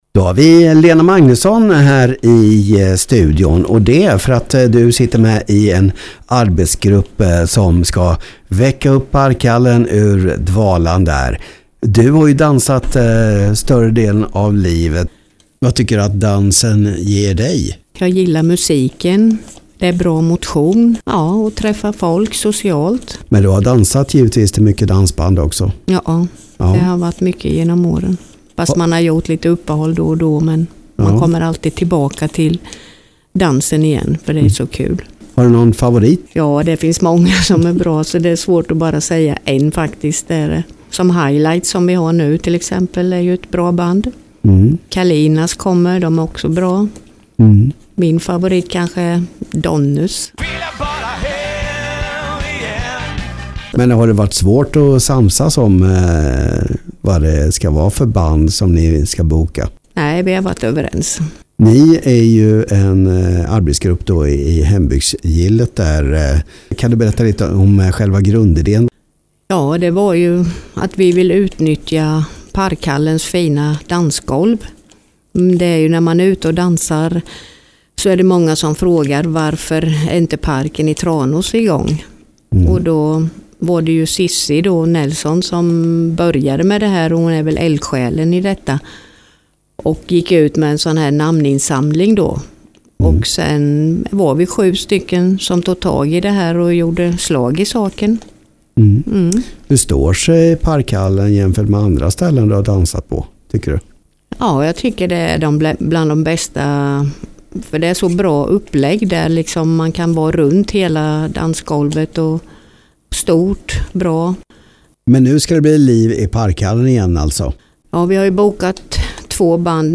Del av radioprogrammet Hitz FM morgon